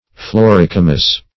Search Result for " floricomous" : The Collaborative International Dictionary of English v.0.48: Floricomous \Flo*ric"o*mous\, a. [L. flos, floris, flower + coma hair.]